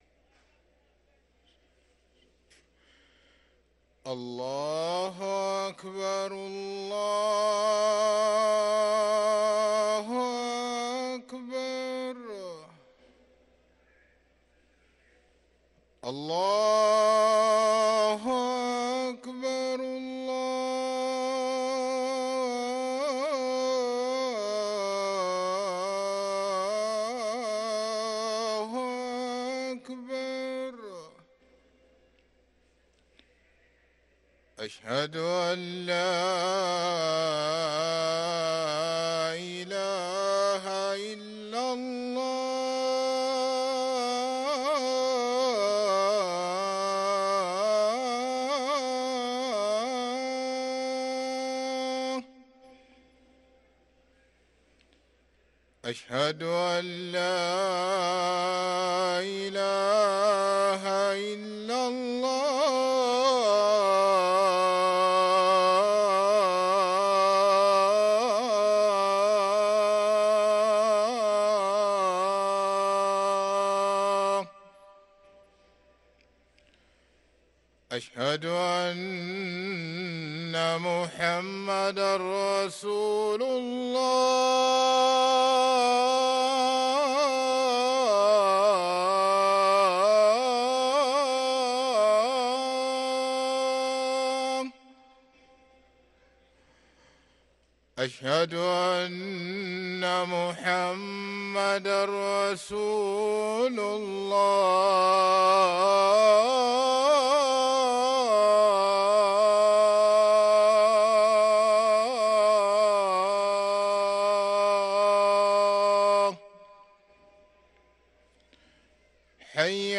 أذان الفجر للمؤذن سعيد فلاته الثلاثاء 10 جمادى الآخرة 1444هـ > ١٤٤٤ 🕋 > ركن الأذان 🕋 > المزيد - تلاوات الحرمين